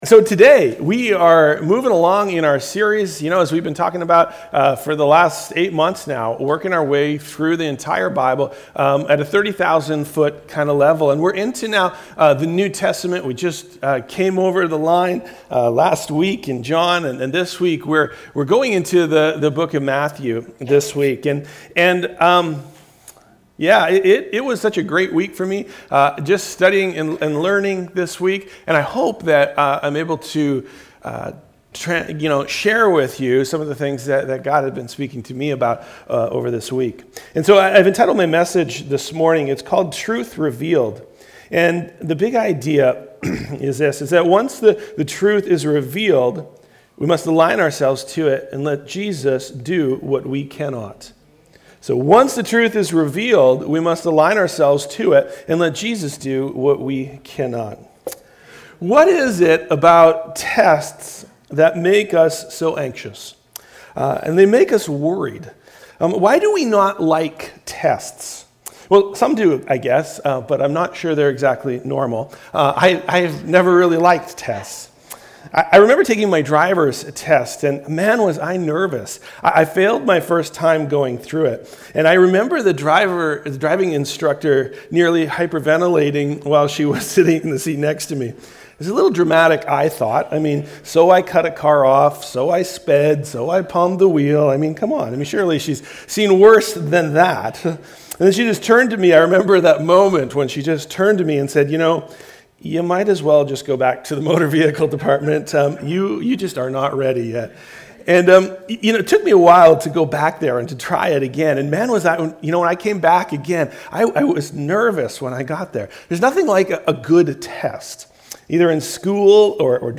Valley Church - Lynn Valley - North Vancouver - Sermons